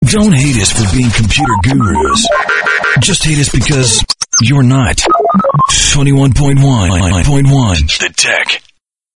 RADIO IMAGING / HOT AC